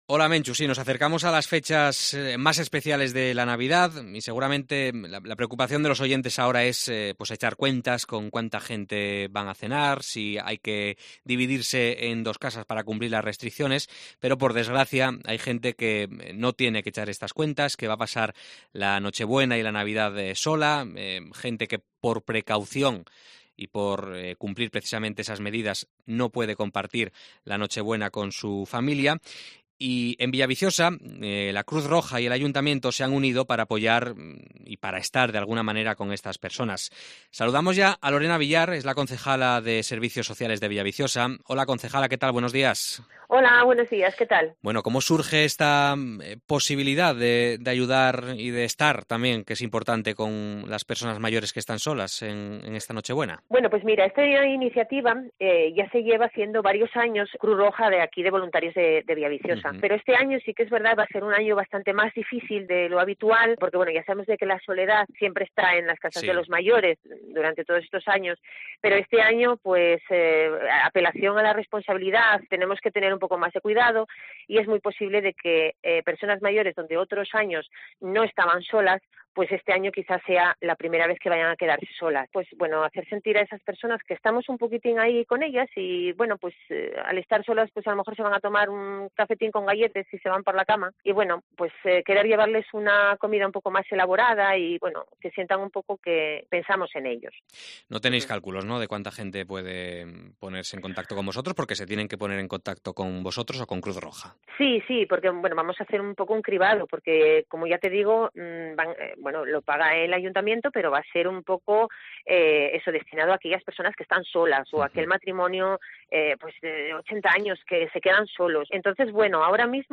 Entrevista a la concejala de Servicios Sociales de Villaviciosa, Lorena Villar